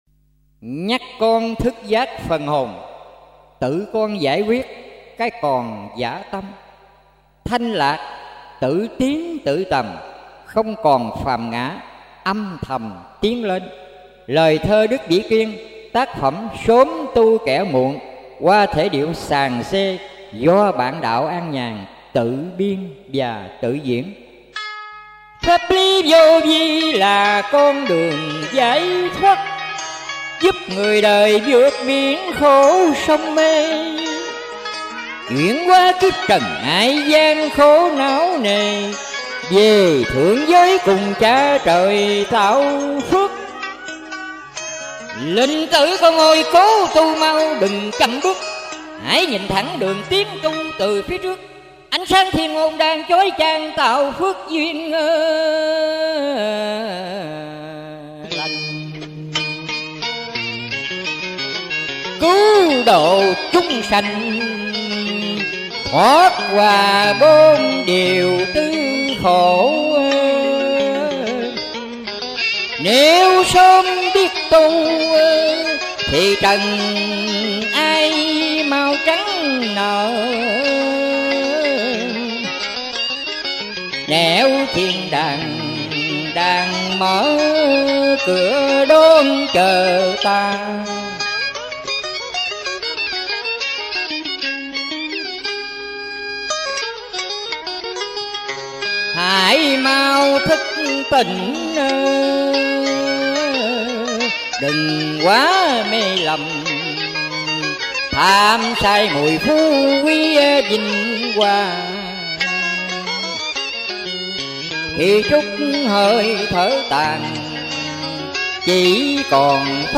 Thiền Ca Vô Vi - Dân Ca & Cải Lương